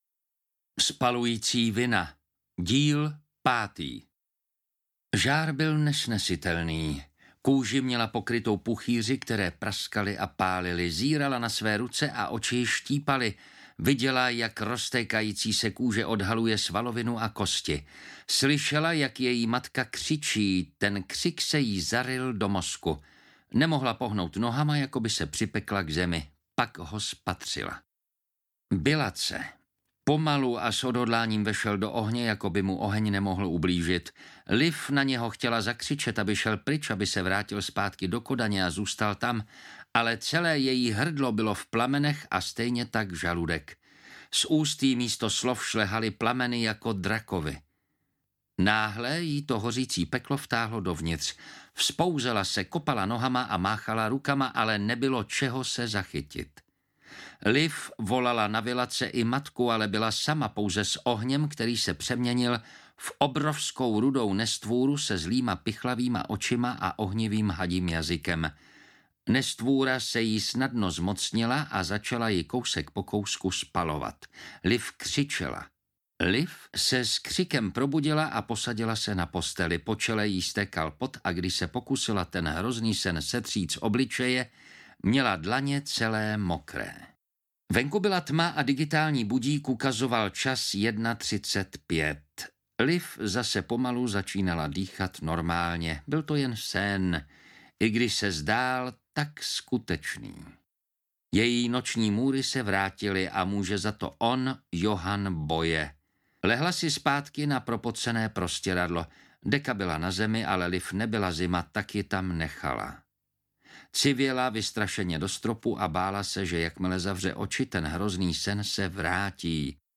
Spalující vina - Díl 5 audiokniha
Ukázka z knihy